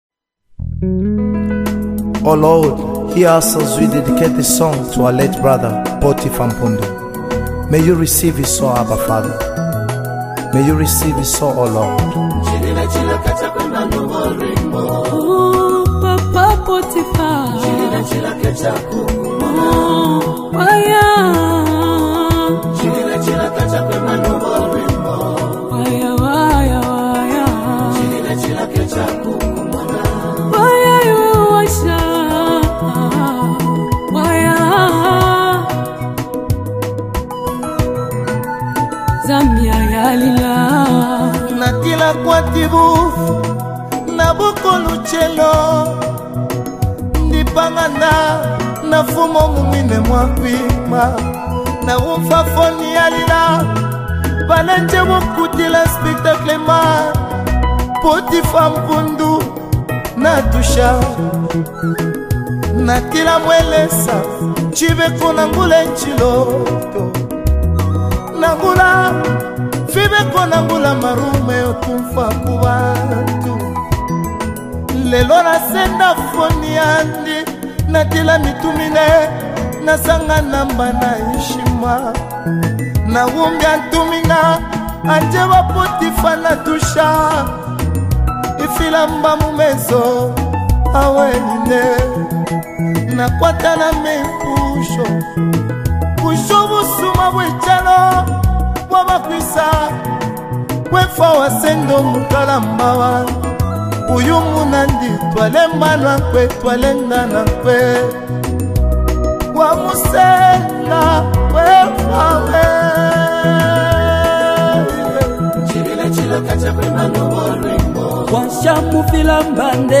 Emotionally rich and deeply moving dedication song
📅 Category: Zambian Classic Dedication Song